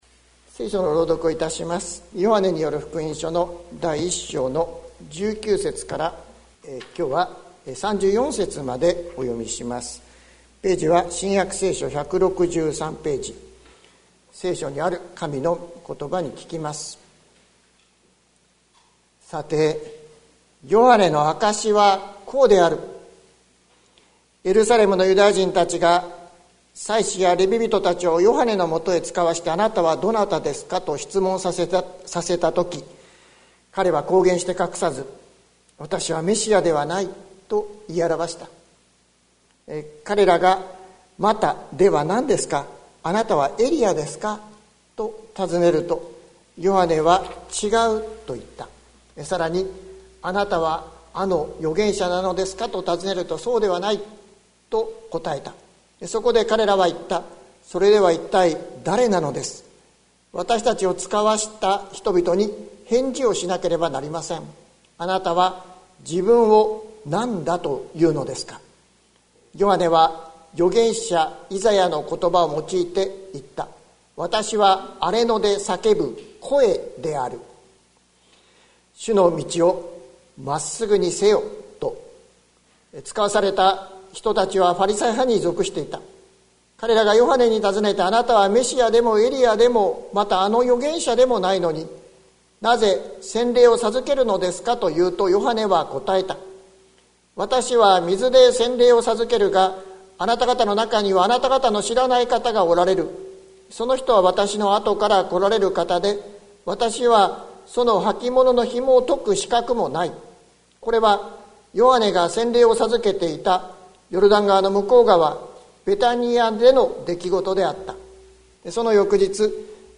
2021年10月24日朝の礼拝「見よ、神の小羊を」関キリスト教会
説教アーカイブ。